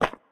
multiple step sounds
step-1.ogg